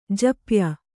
♪ japya